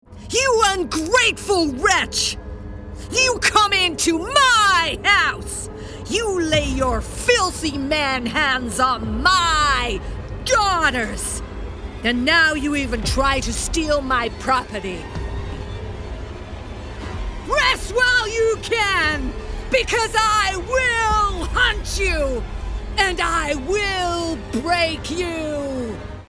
40/50's Northern,
Angry Witch